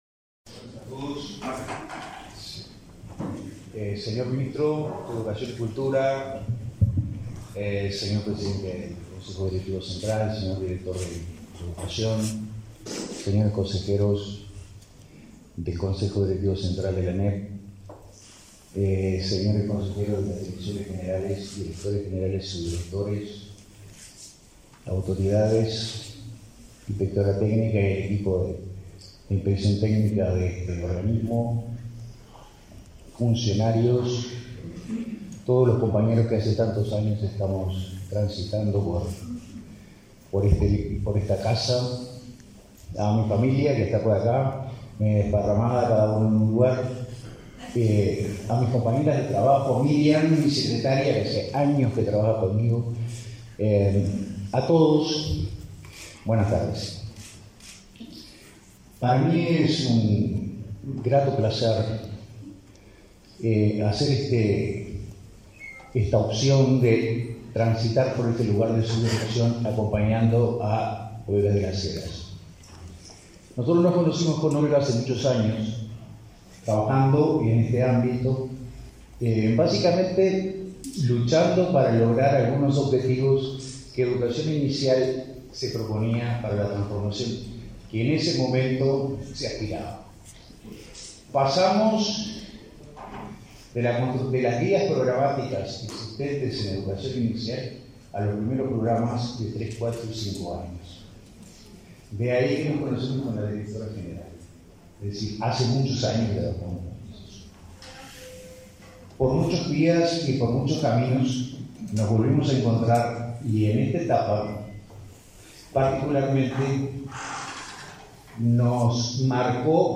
Acto de asunción de autoridades de Educación Inicial y Primaria